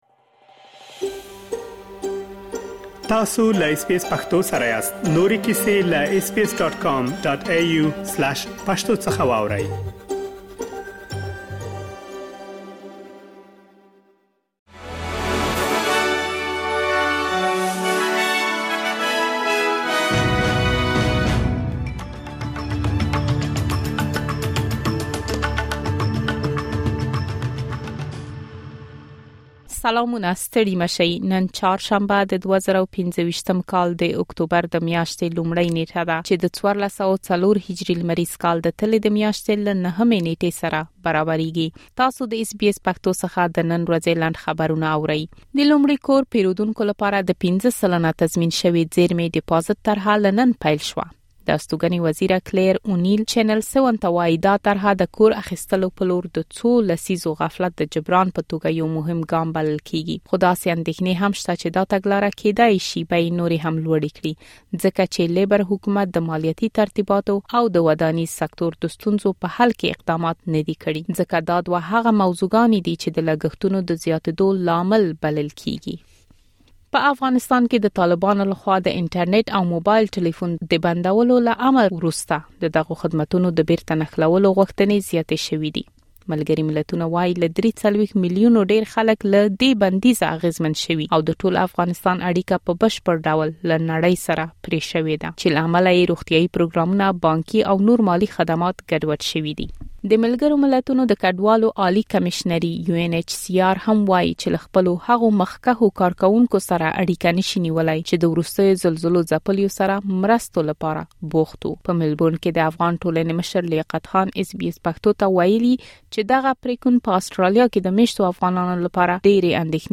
د اس بي اس پښتو د نن ورځې لنډ خبرونه|۱ اکتوبر ۲۰۲۵